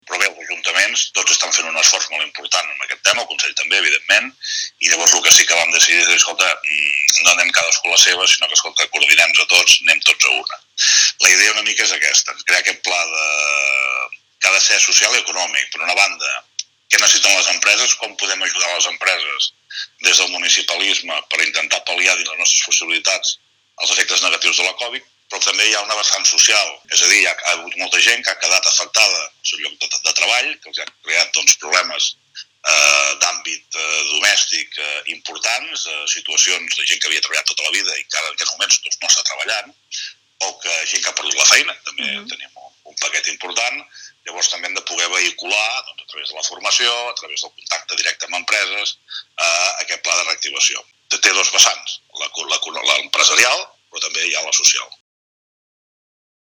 Xavier Dilmé explica que el Pla de Reactivació Socioeconòmica que ha de servir per pal·liar aquests efectes de la pandèmia tindrà dues vessants: una empresarial i una social (per a persones que s’han quedat sense feina: formació, contacte amb empreses…).